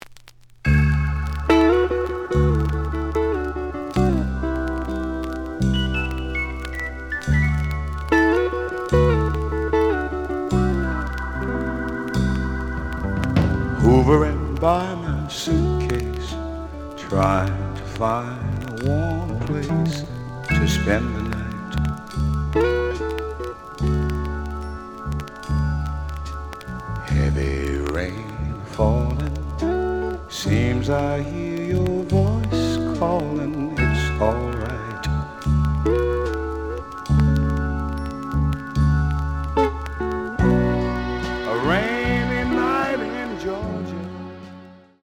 The audio sample is recorded from the actual item.
●Genre: Soul, 60's Soul